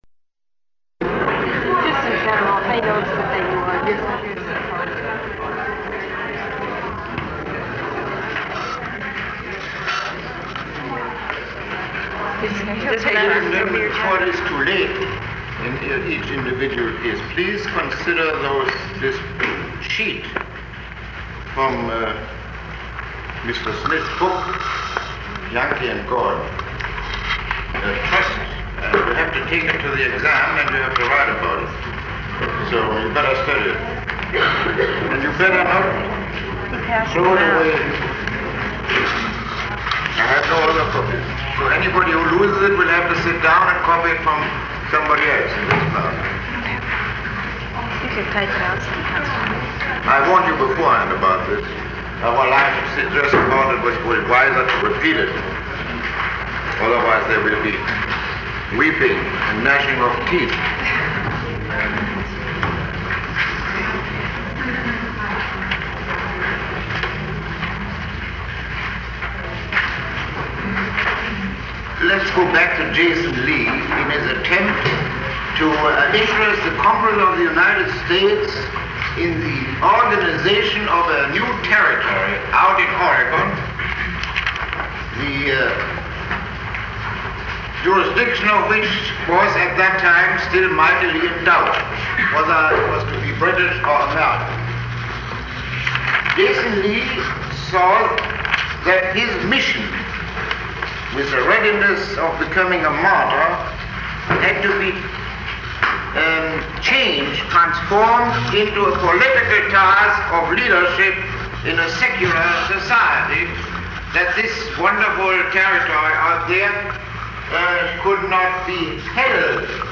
Lecture 29